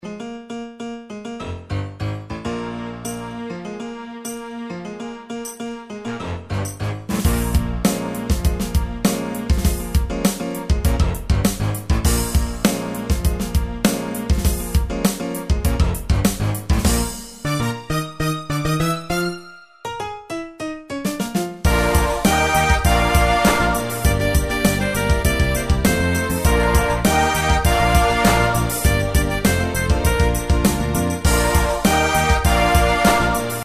Tempo: 100 BPM.
MP3 with melody DEMO 30s (0.5 MB)zdarma